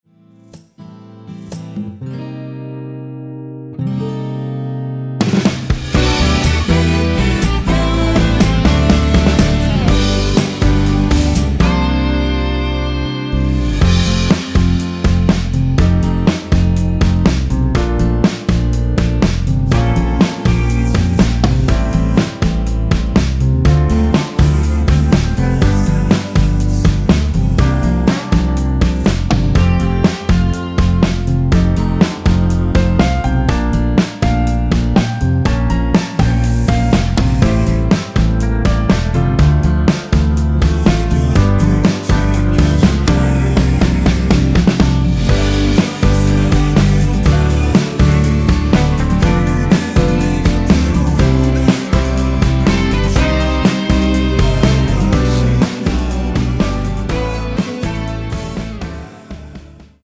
원키에서(-2)내린 코러스 포함된 MR입니다.(미리듣기 확인)
전주없이 노래가 시작 되는 곡이라 전주 1마디 만들어 놓았습니다.
앞부분30초, 뒷부분30초씩 편집해서 올려 드리고 있습니다.